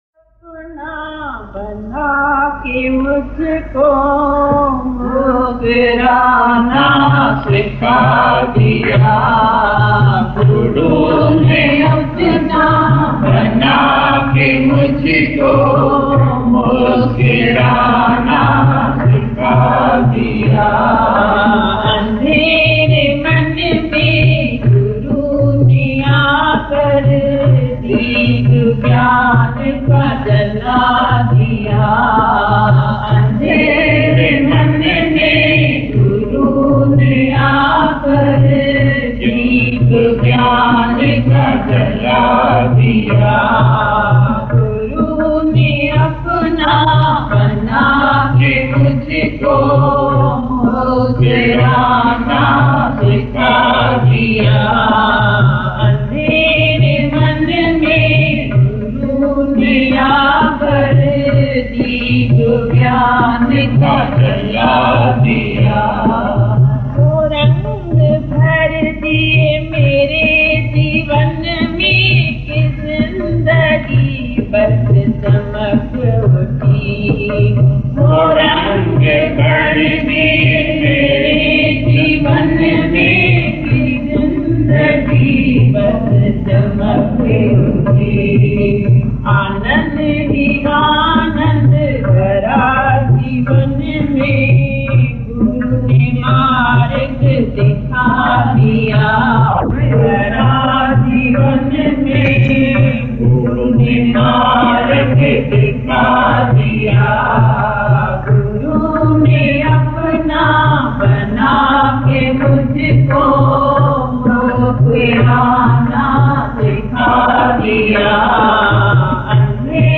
Guru-Ne-Apna-Banaa-ke-Mujhko-Muskurana-Bhajan.mp3